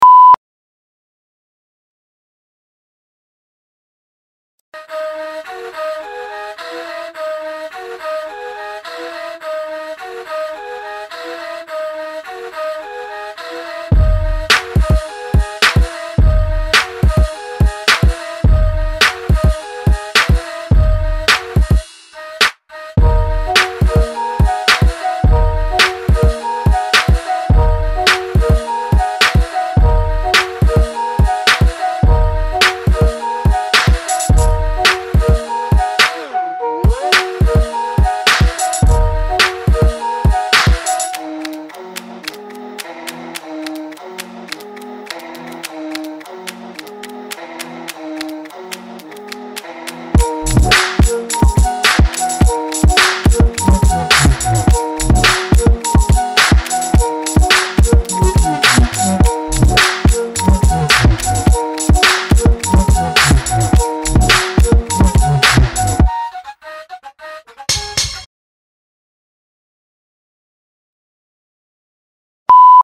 *bleep: Are you on your spot?
*3 seconds silence: to look better on screen to be sure that you are not walking anymore
*few seconds silence: stay in your place till you hear the next bleep